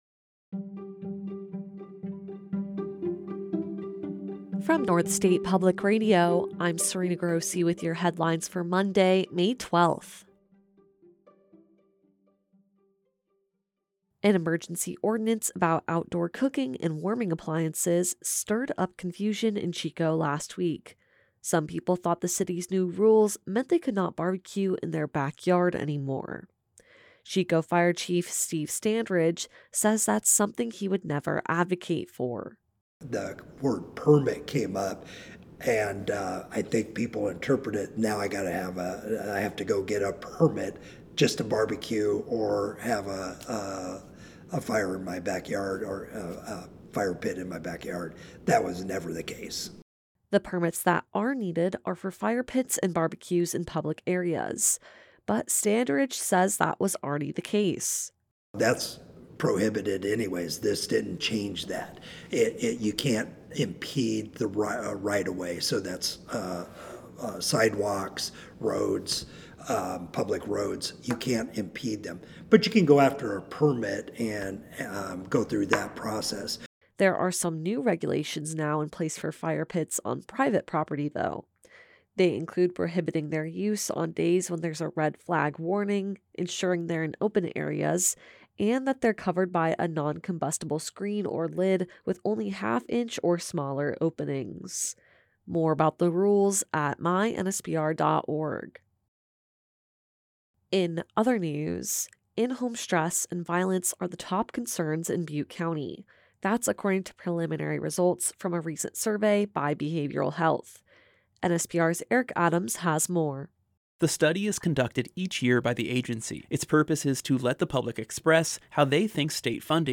The latest North State and California news on our airwaves for Monday, May 12, 2025.